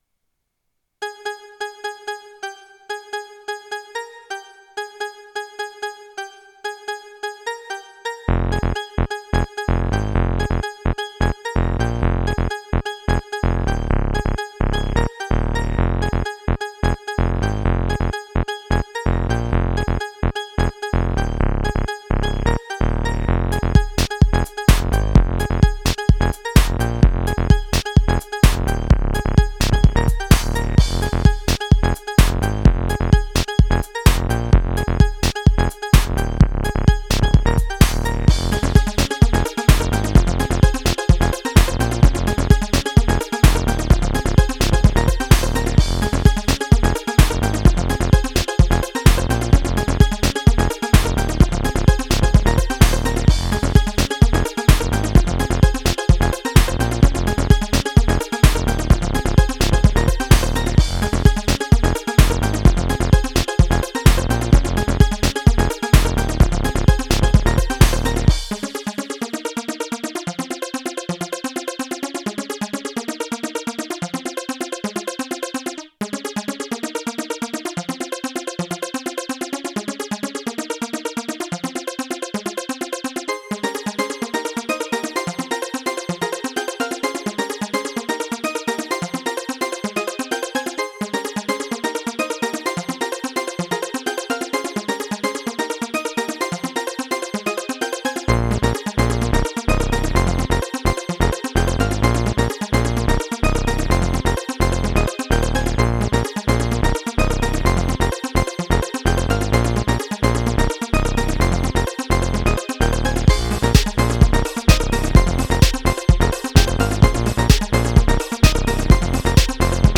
I wanted something energetic that you, the listener, could include in your own exercise routine, so I decided it was up to me to lay down some beats on my trusty Casiotone CT-S 300 at 128 beats per minute.